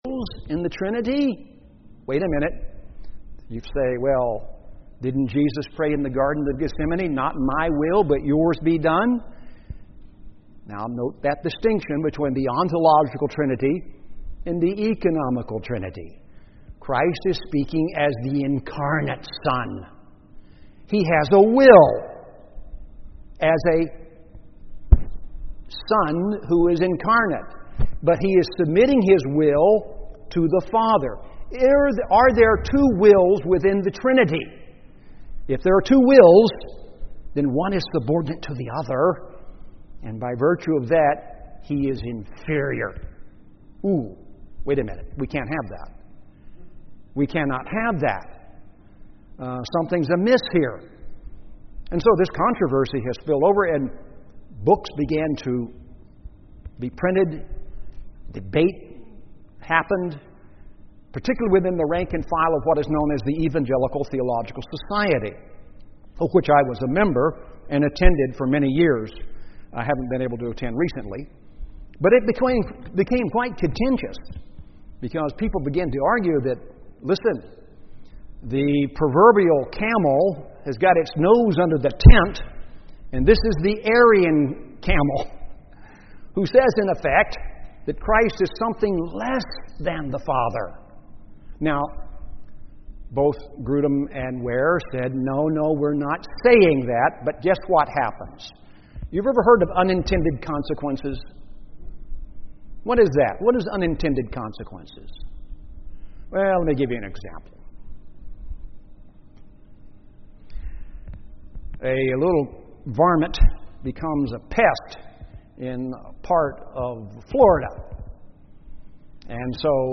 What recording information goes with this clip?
Audio missing the first couple of minutes.